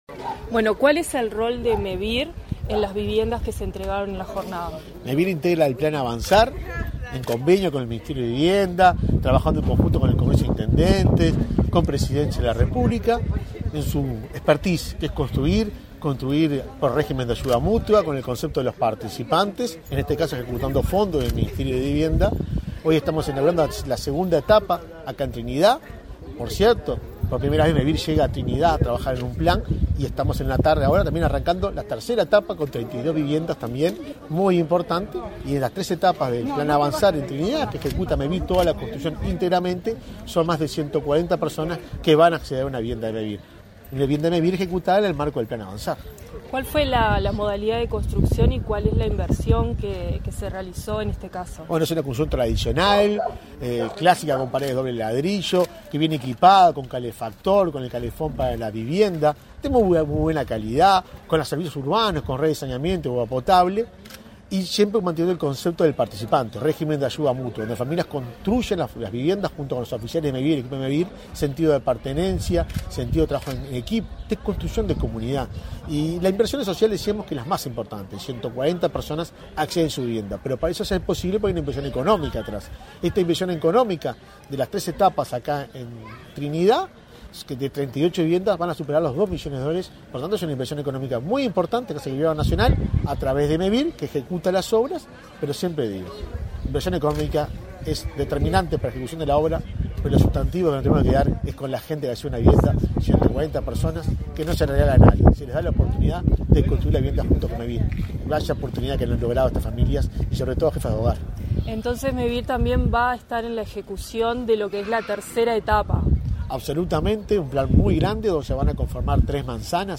Entrevista al presidente de Mevir, Juan Pablo Delgado
Tras el evento, realizó declaraciones a Comunicación Presidencial.